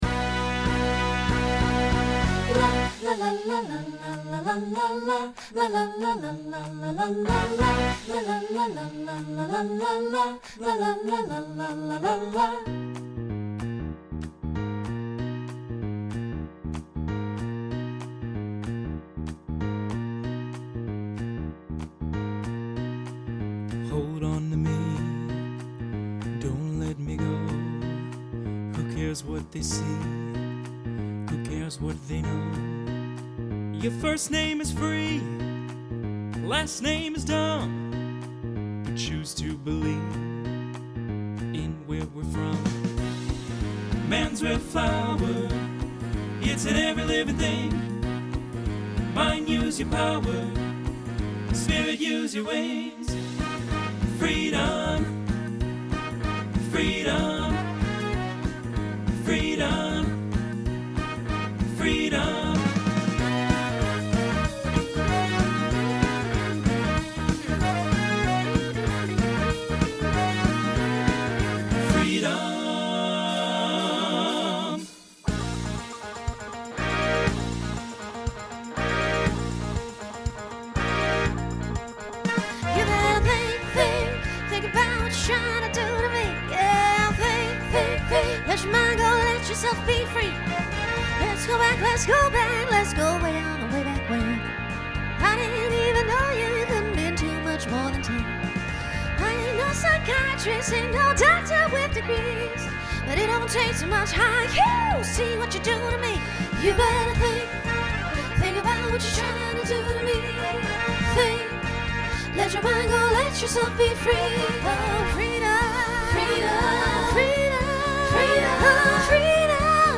Starts TTB, then SSA, then finishes SATB.
Pop/Dance , Rock
Voicing Mixed